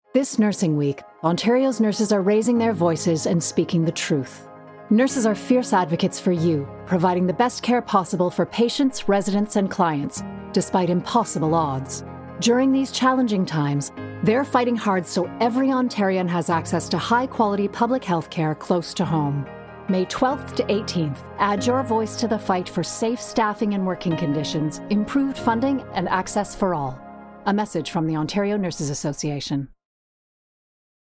Public Service Announcement (PSA) Recording